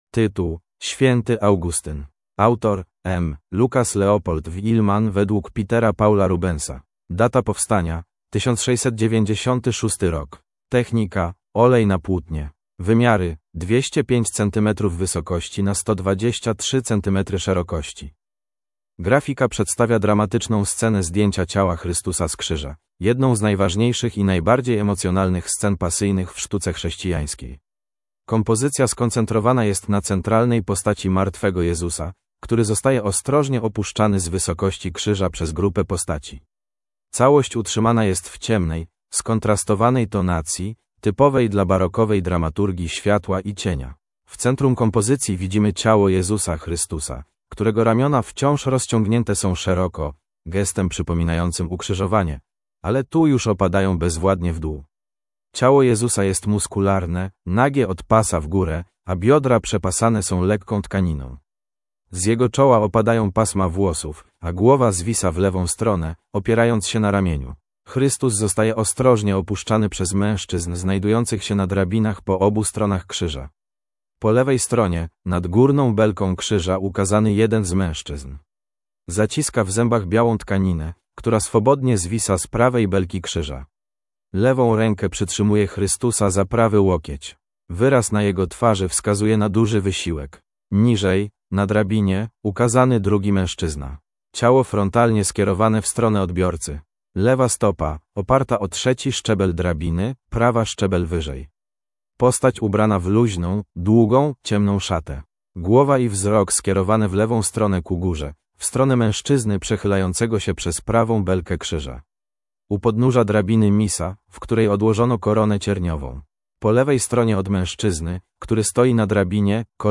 MNWr_audiodeskr_Swiety_Augustyn.mp3